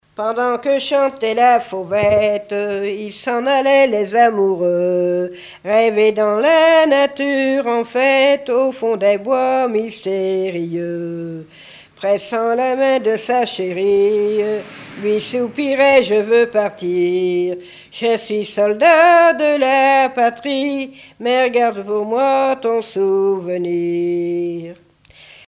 chansons patriotiques et complaintes
Pièce musicale inédite